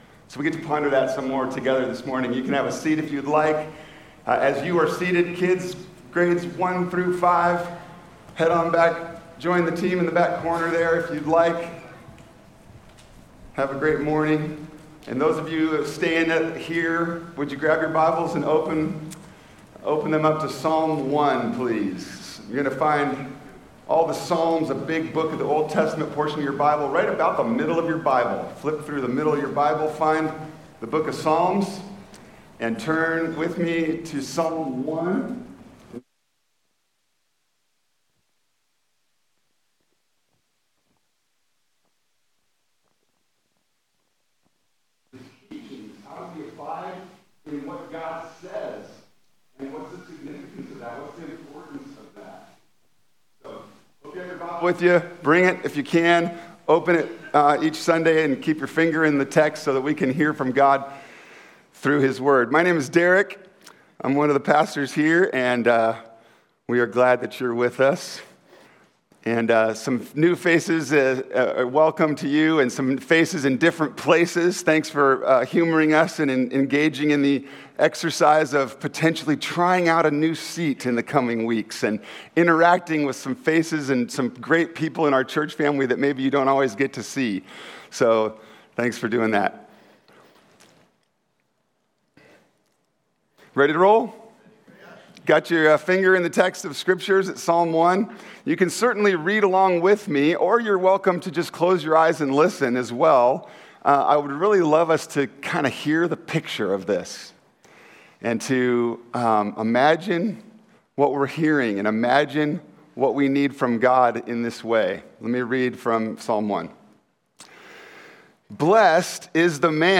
Faith Church (Dallas, OR) Sermon podcast
Weekly Bible Teaching